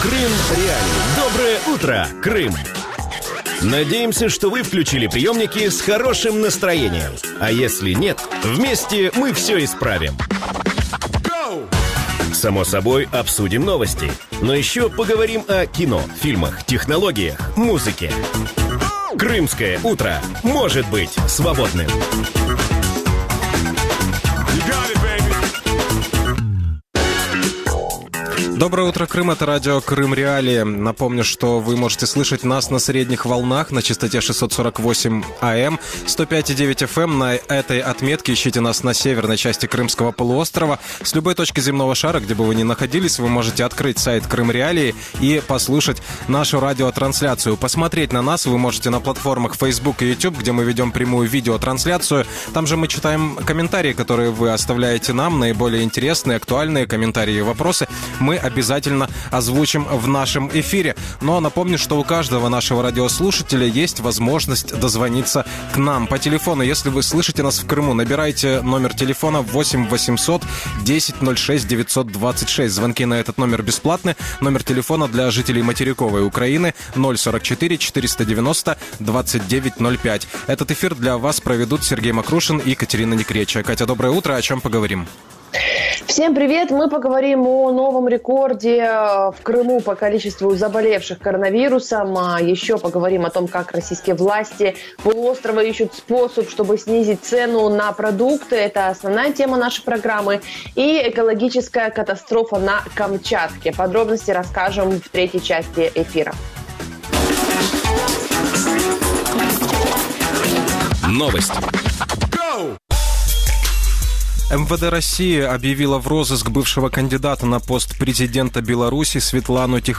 В Крыму новый рекорд по количеству заболевших на коронавирус | Утренние новости Радио Крым.Реалии